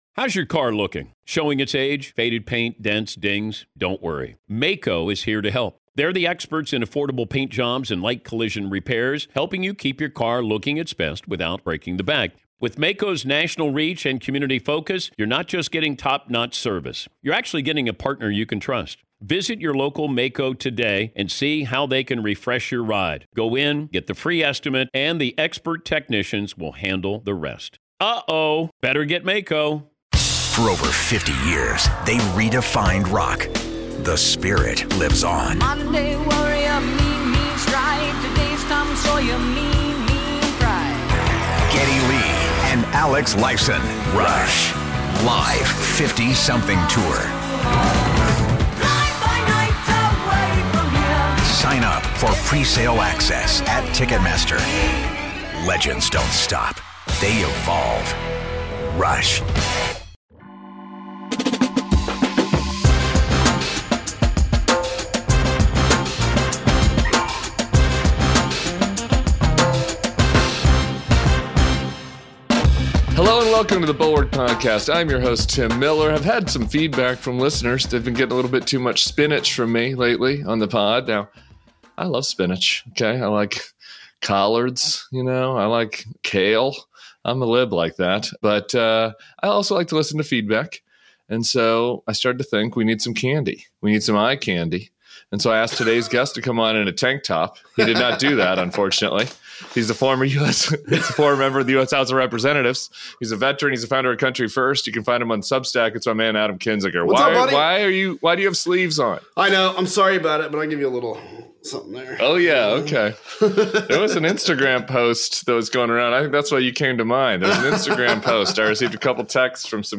Adam Kinzinger joins Tim Miller to talk about the merits of the indictment, why we can’t afford to be intimidated by it and what comes next. They also discuss why Pete Hegseth called generals to a mysterious meeting, how the administration released the military record of New Jersey’s Democratic candidate for governor to her political opponent and how Democrats need to play the fight over a looming government shutdown.